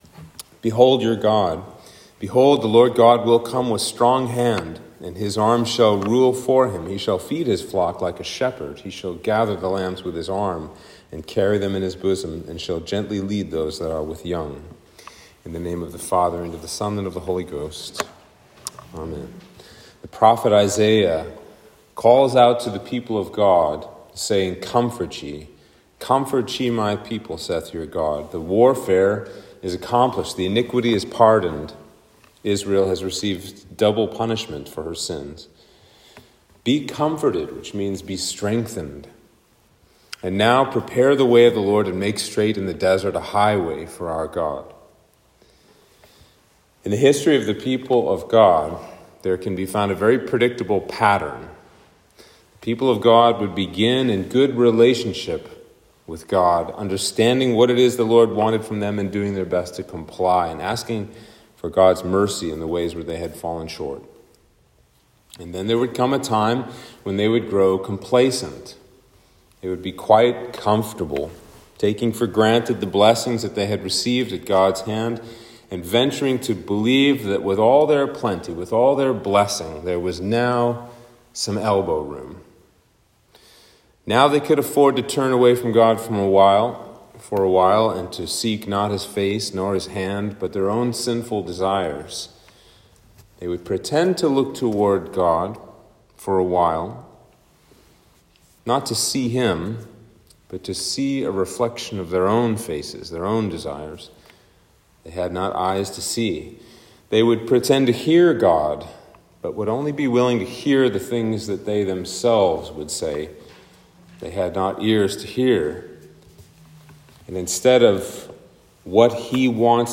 Sermon for Advent 4